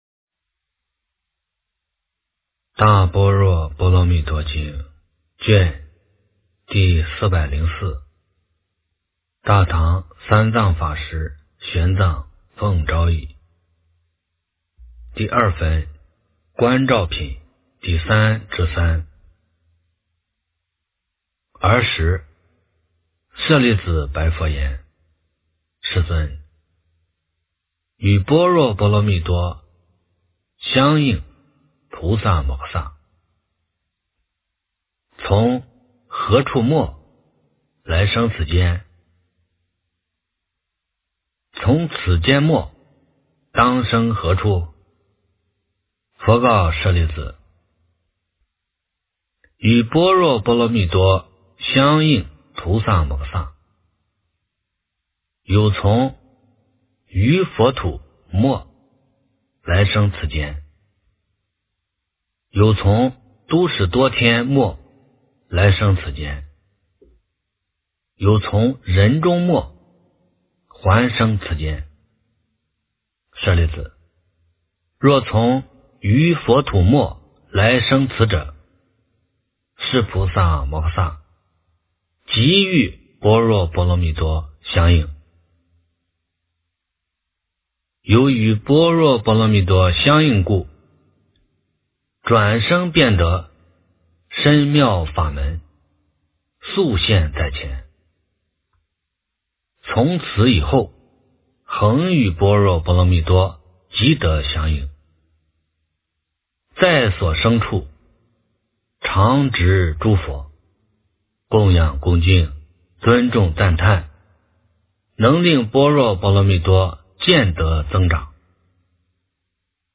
大般若波罗蜜多经404卷 - 诵经 - 云佛论坛